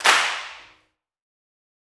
008_jc_ss_church_clap_wide.wav